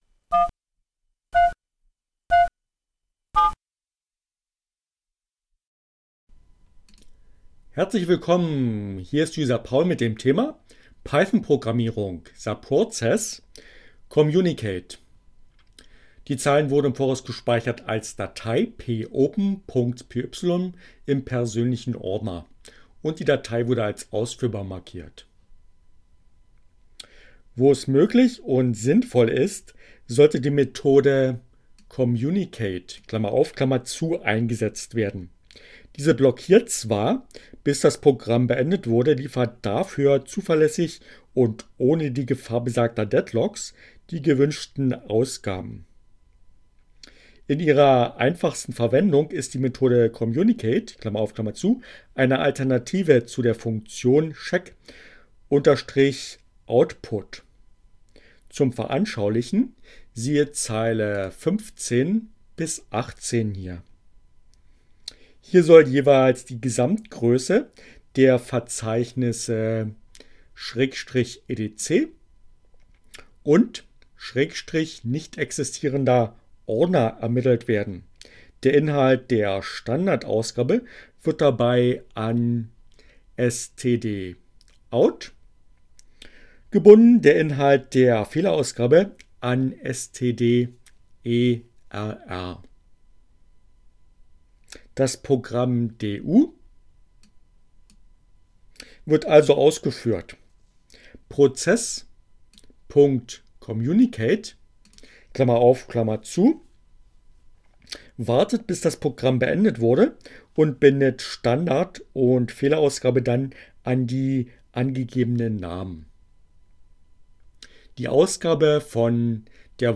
Tags: CC by-sa, Linux, Neueinsteiger, ohne Musik, screencast, Python, Programmierung, subprocess